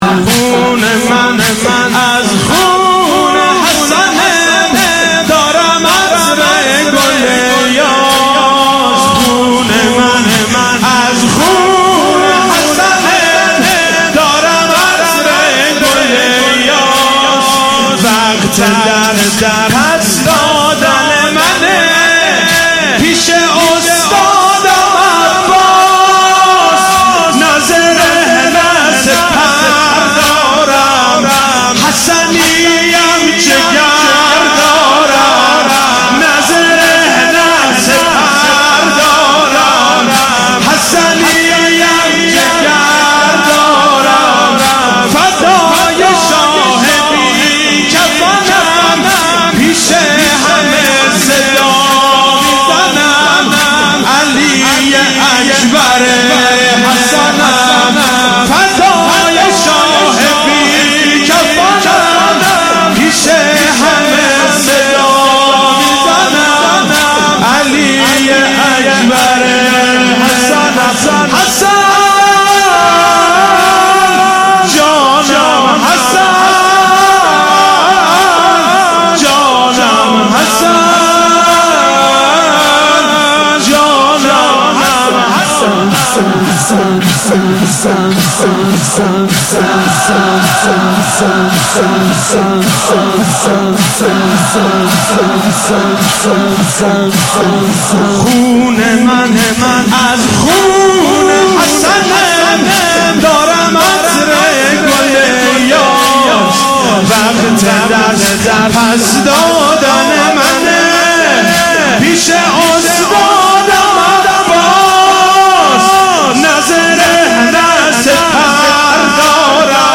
زمینه مداحی